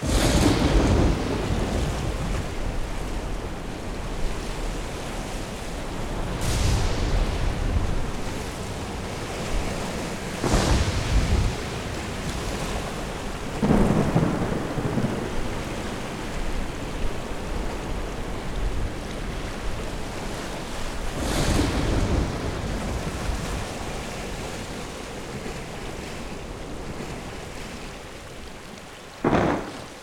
darksea_sound_loop.wav